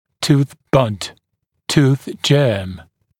[tuːθ bʌd] [tuːθ ʤɜːm][ту:с бад] [ту:с джё:м]зубной зачаток